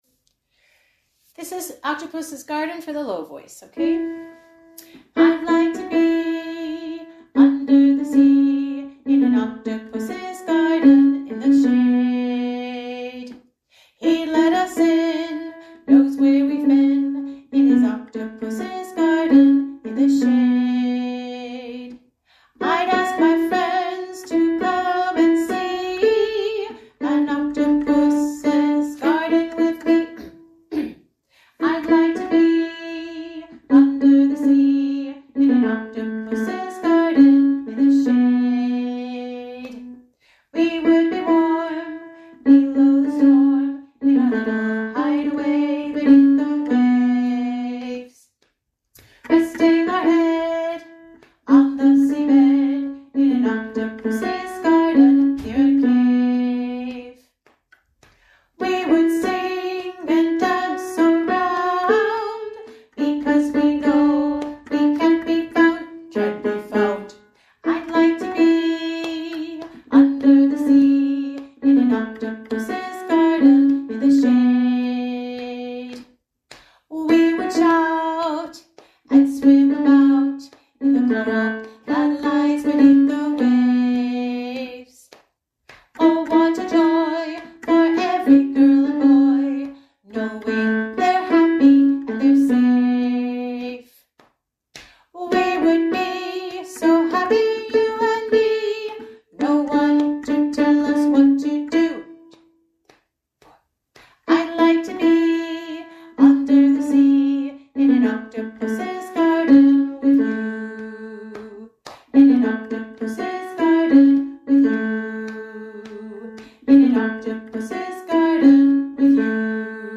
Sing along tracks